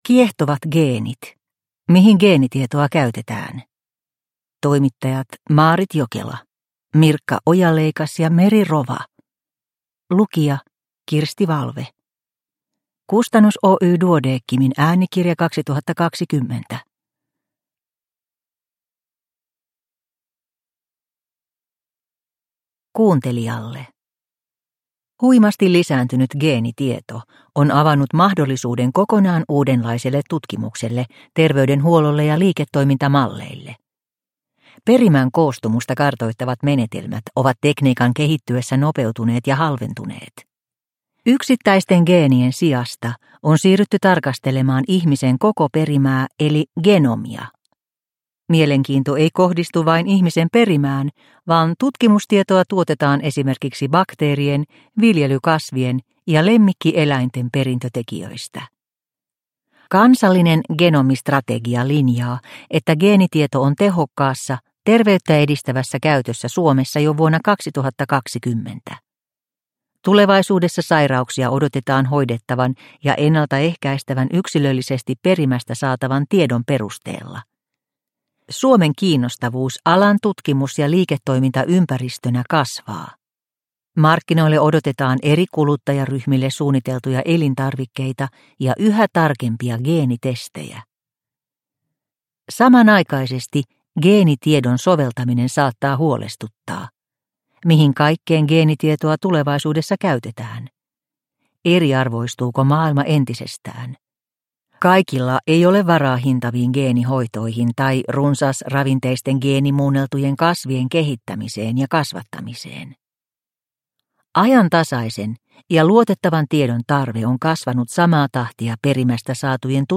Kiehtovat geenit (ljudbok) av - | Bokon